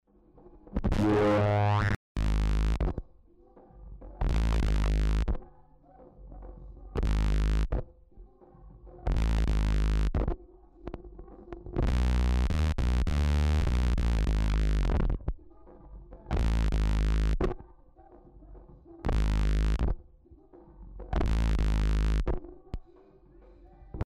エレクトリックベースに、ファズ系のドライブとフィルター系のエフェクトが使われているものと思います。
かなり歪んでいるがゆえなのか、DI（ライン）にも他の楽器がカブっています。
DIトラックの未処理はこんな感じ
ベースの刻みの合間にボーカルやドラムがふわっとカブっているのが分かります。